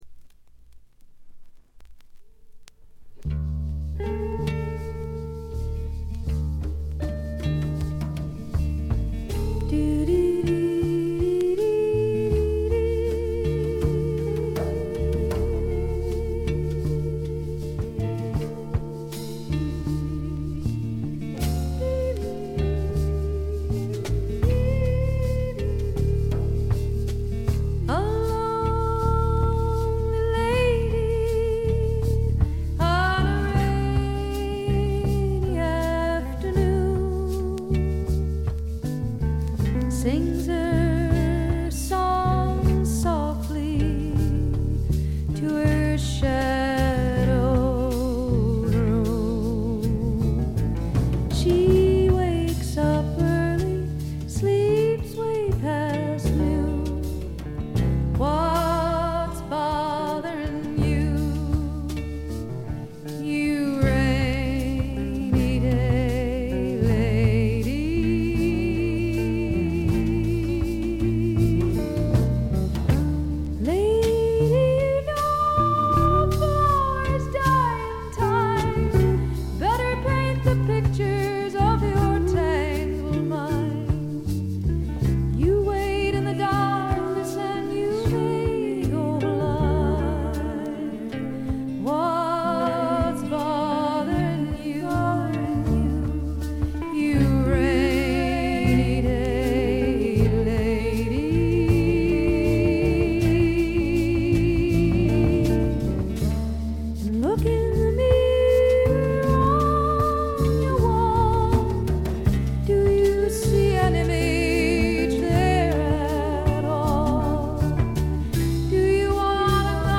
ところどころでチリプチ。大きなノイズはありません。
試聴曲は現品からの取り込み音源です。